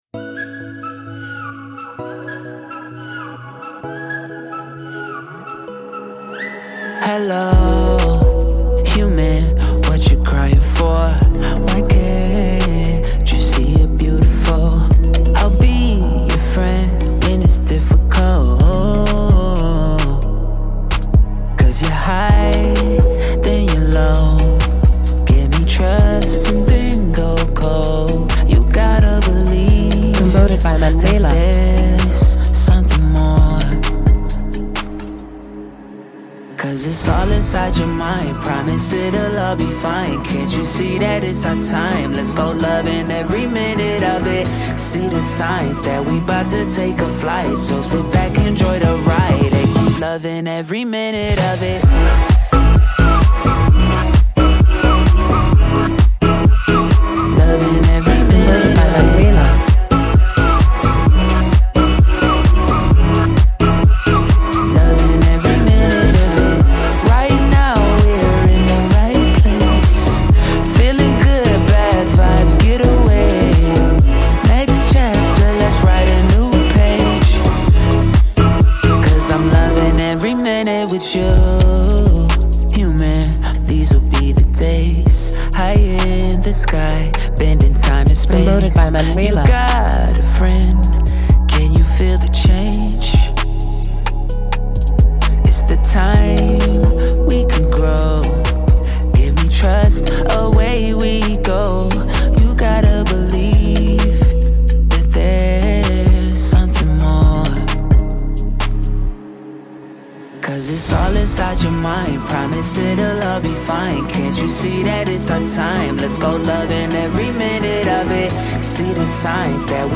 groovy à souhait
Radio Edit